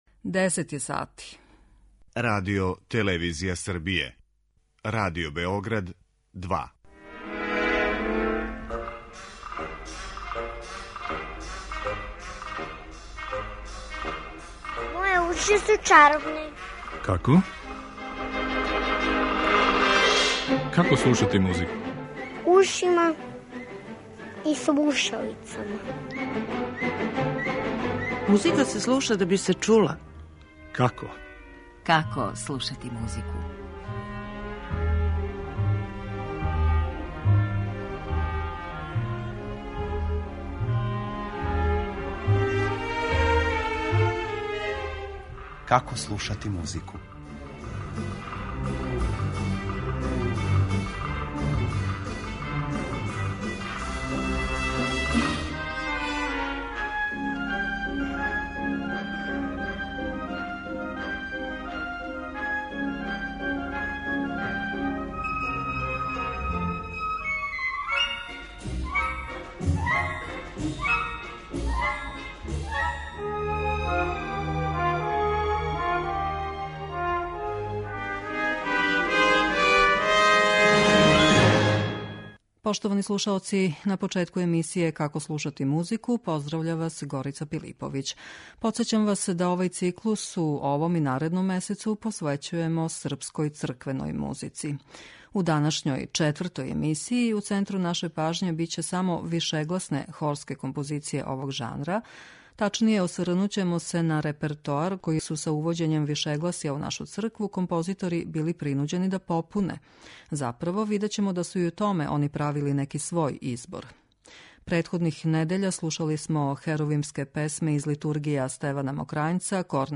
Српска црквена музика
У циклусу Како слушати музику, посвећеном српској црквеној музици, дошао је ред и на четврту емисију, у којој ће у центру пажње бити само вишегласне хорске композиције овог жанра.